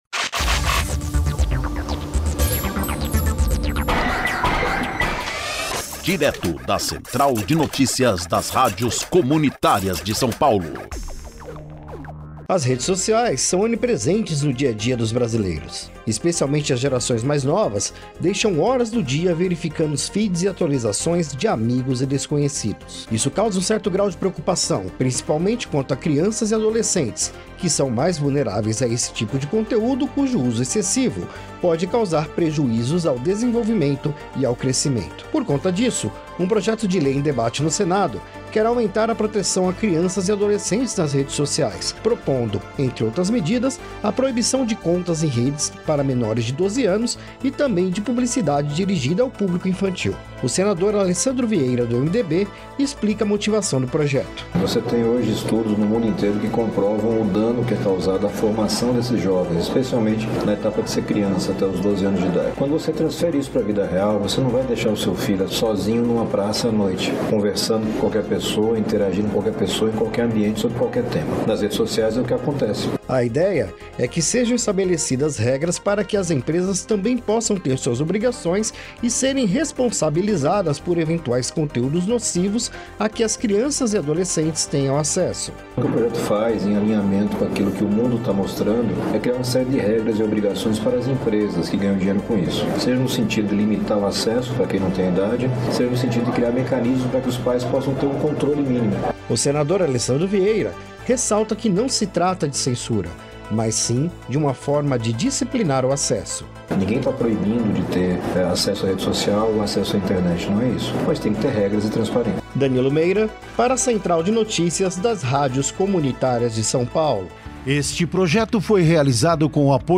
O Senador Alessandro Vieira, do MDB, explica a motivação do projeto: